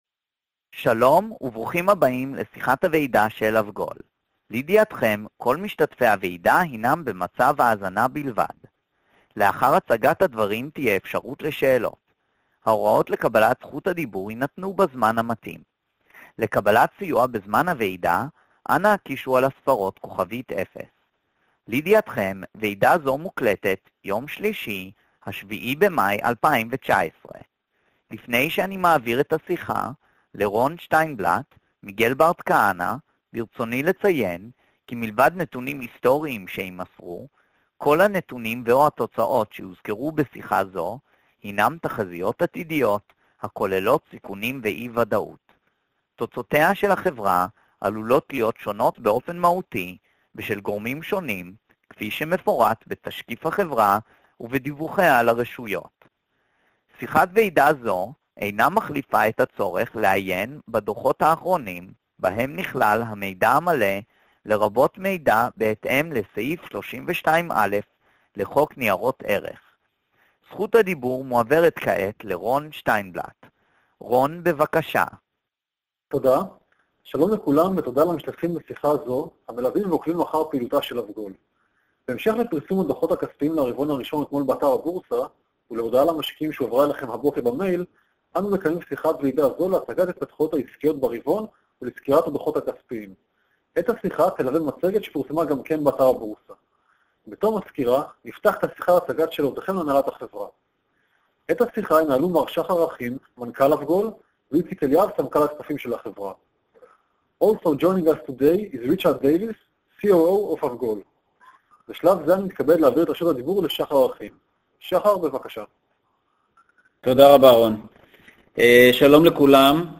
avgol-2019-q1-conference-call.mp3